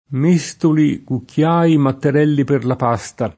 m%Stoli, kukkL#i, matter$lli per la p#Sta] (Valgimigli); con quelle bazze di legno fatte a méstolo [